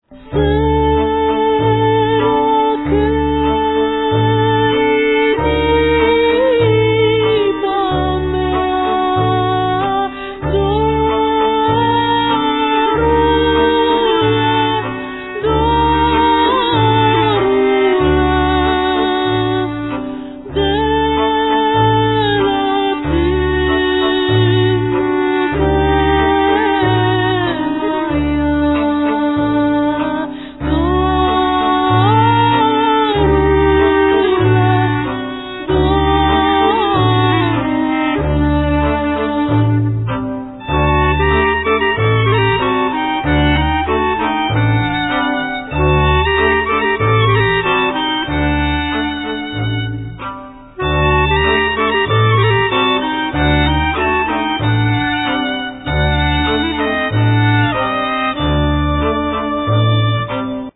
Tampoura, Gajda, Percussions, Chour, Vocal
Violin, Darbuka, Percussions, Choir, Vocal
5 strings fiddle, Percussions, Choir. Vocal
Davul, Darbuka, Pandeireta, Choir, Vocal
Double bass, Contras, Choir